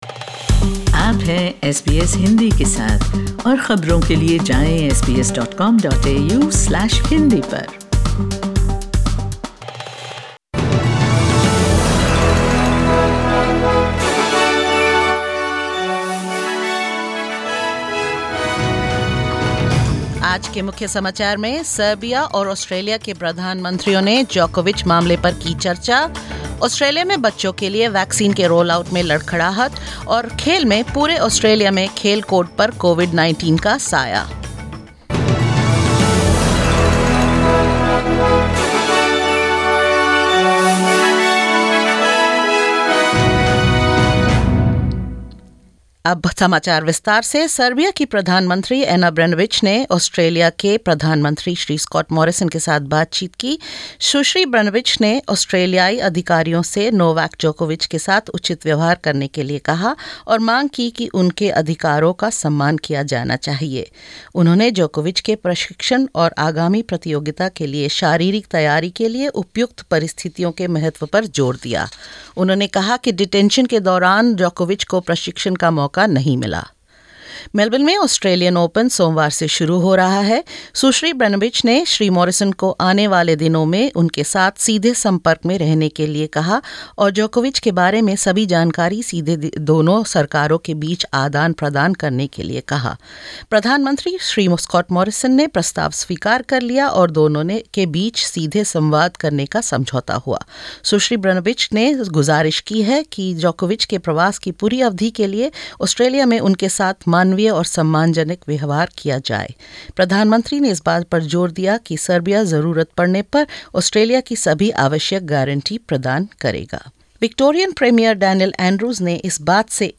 In this latest SBS Hindi news bulletin: Novak Djokovic controversy continues as Serbian Prime Minister Ana Brnabic responds; The vaccine rollout for children stumbles due to supply and delivery concerns; Two more A-League Men's competitions postponed due to coronavirus cases and more.